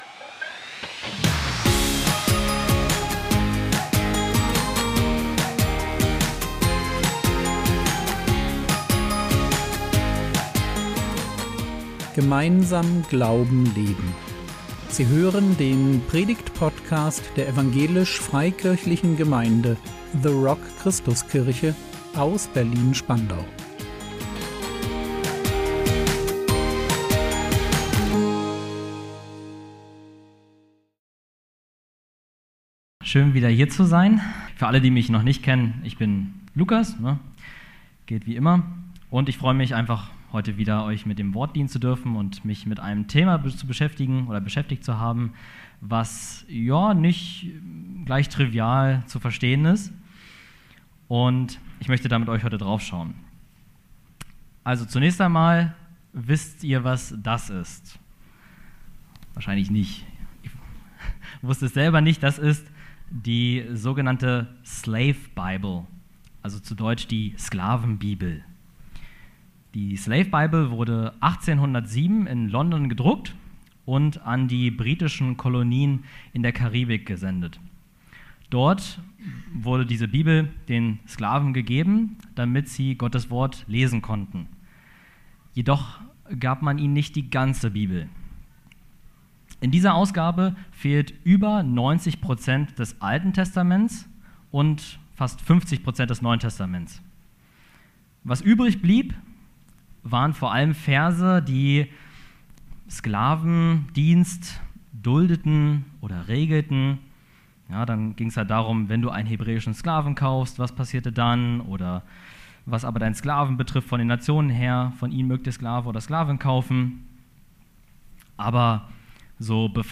Sklaverei im Alten Testament | 31.08.2025 ~ Predigt Podcast der EFG The Rock Christuskirche Berlin Podcast